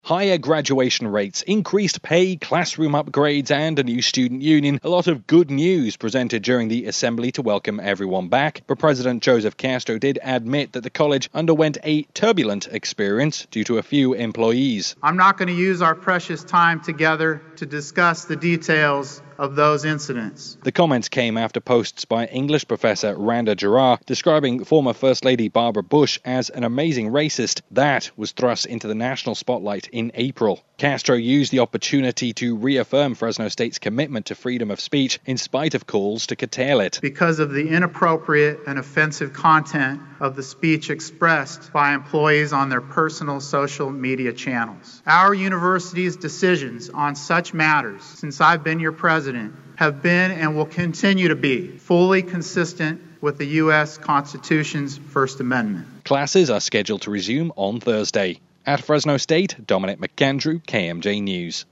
FRESNO (KMJ) — Fresno State faculty and staff have been officially welcomed back to campus, but the Fall Assembly took place under the shadow of what President Joseph Castro described as “turbulence” from earlier in the year.